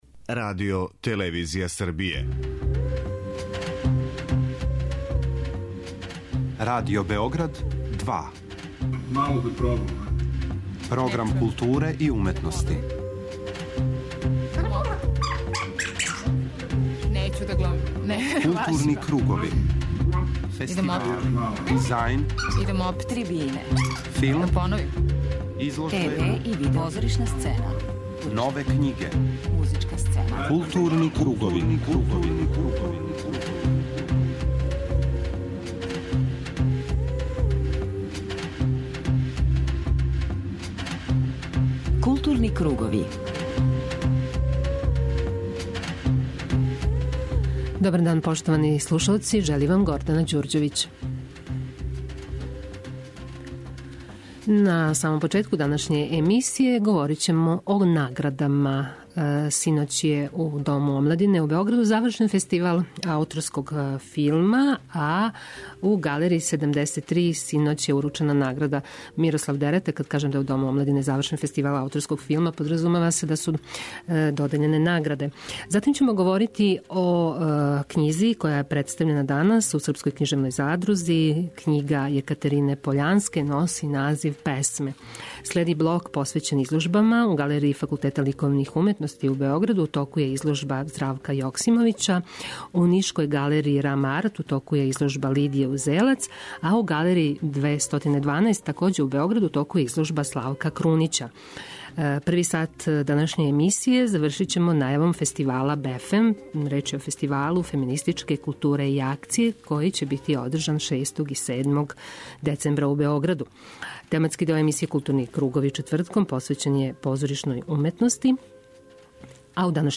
Гост тематског блока Маске је Егон Савин, а поводом београдске премијере представе "Мрешћење шарана", Александра Поповића, у адаптацији и режији нашег госта, у извођењу Народног позоришта Републике Српске из Бања Луке.